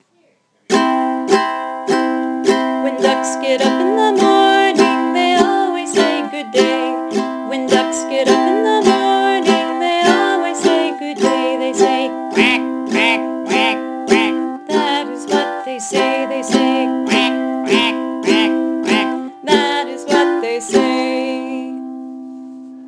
Another one chord song is Are You Sleeping? or Frere Jacques.  For this one, I added an up-strum, by moving my fingers up the bottom strings briefly in between downstrokes.